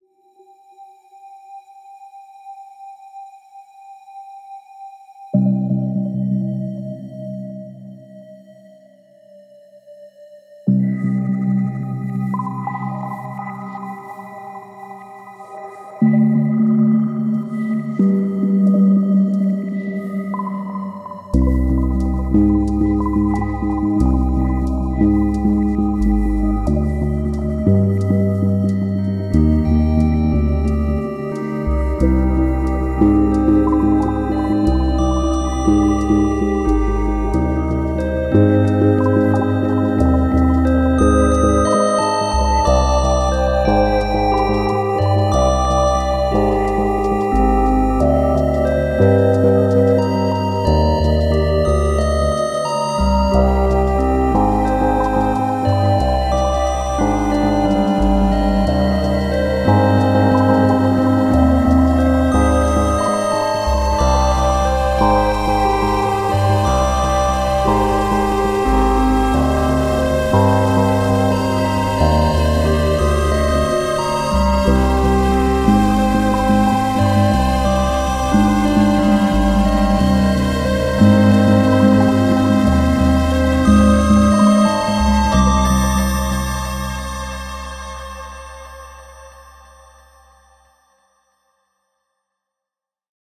Psychedelic synths escalating to a dramatic climax.